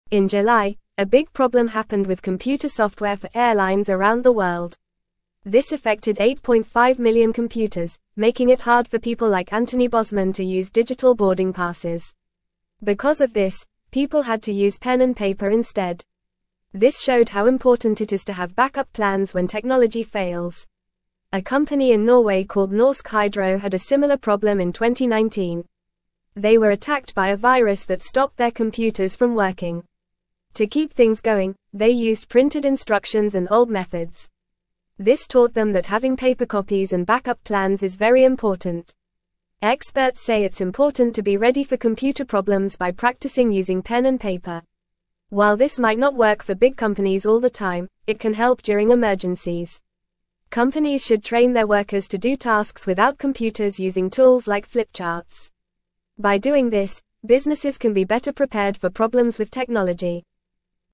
English-Newsroom-Lower-Intermediate-FAST-Reading-Paper-is-important-when-computers-dont-work.mp3